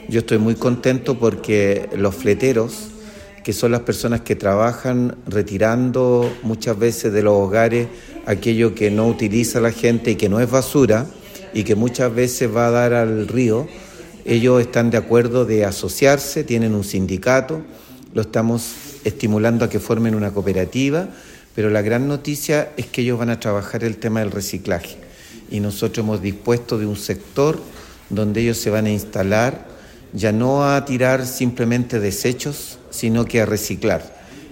El alcalde de Quillota, Luis Mella, valoró el compromiso de los fleteros y destacó la importancia del trabajo conjunto: